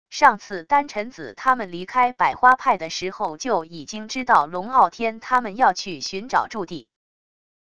上次丹尘子他们离开百花派的时候就已经知道龙傲天他们要去寻找驻地wav音频生成系统WAV Audio Player